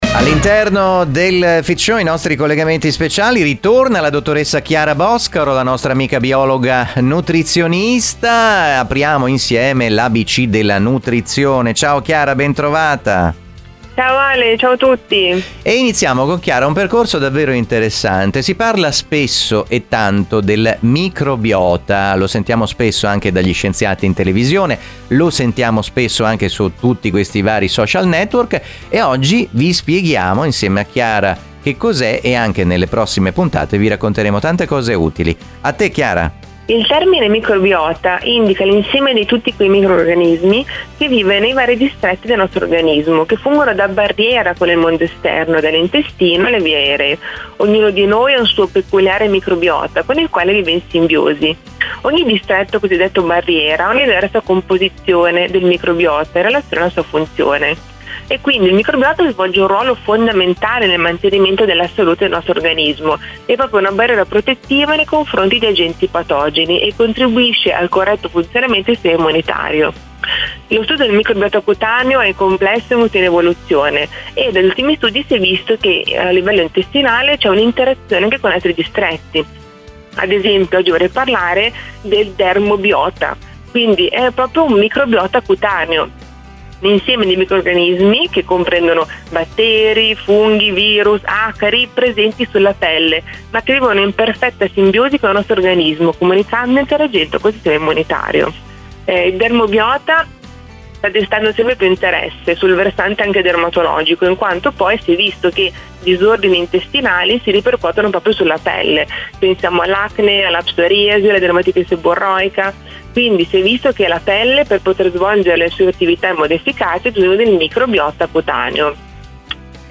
Un talk show con ospiti illustri e tanti personaggi, giornalisti, opinionisti ed esperti.